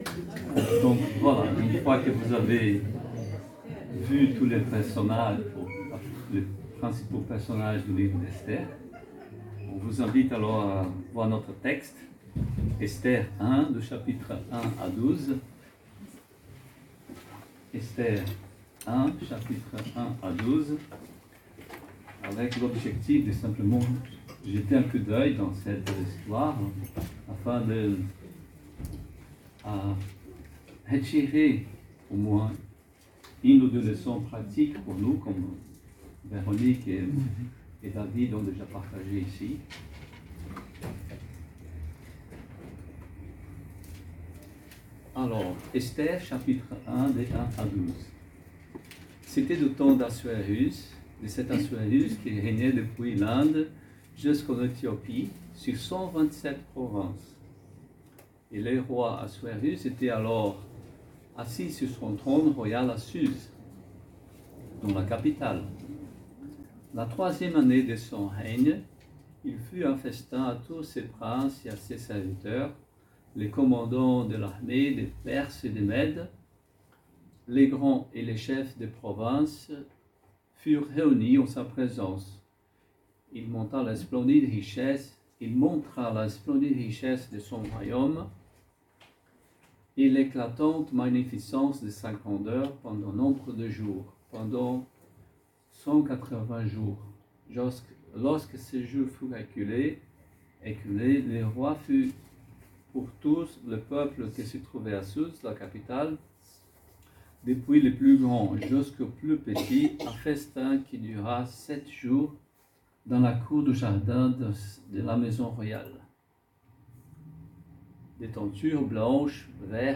Prédication du 26 octobre 2026